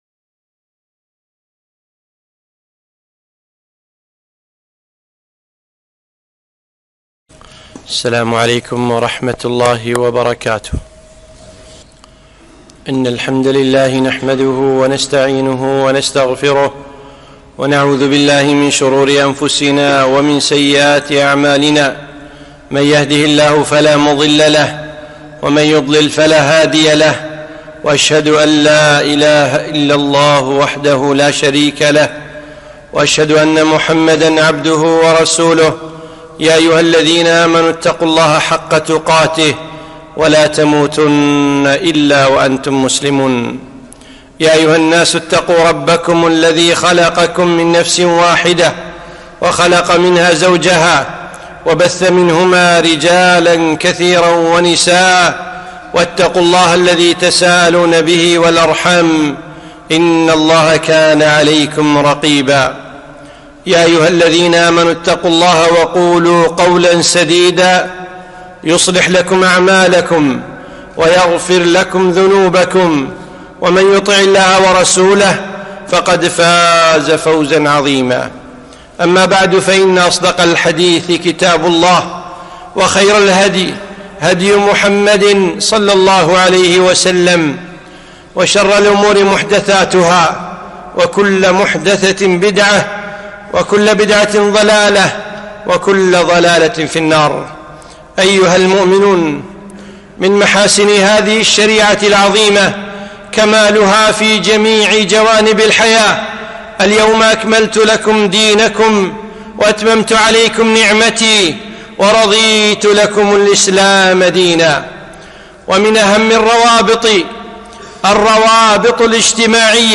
خطبة - الطلاق